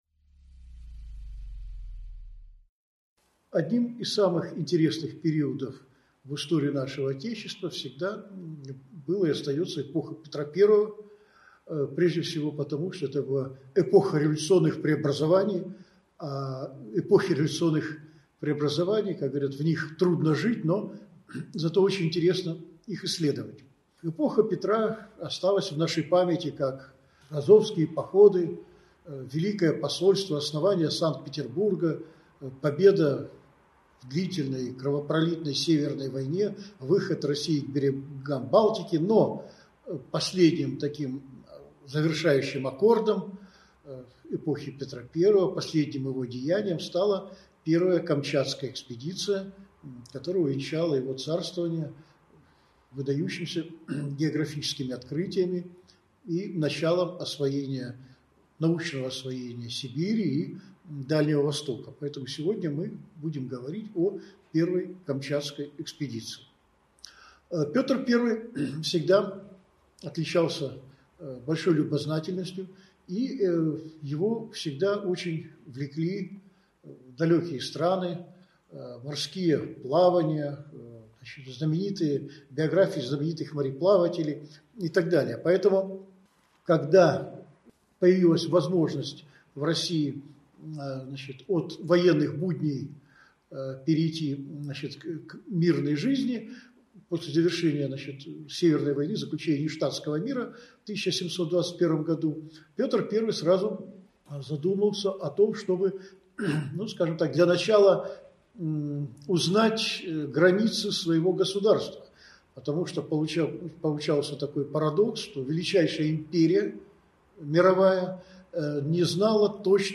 Аудиокнига Великая Северная экспедиция. Мифы и правда | Библиотека аудиокниг